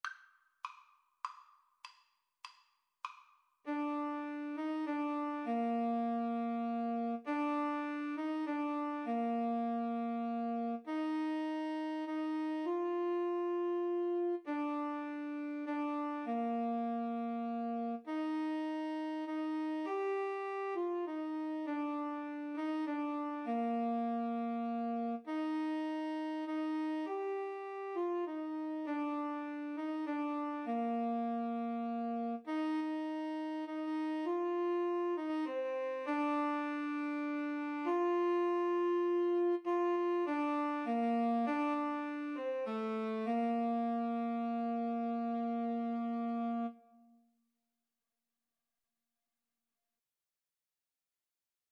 Alto SaxophoneTenor Saxophone
6/8 (View more 6/8 Music)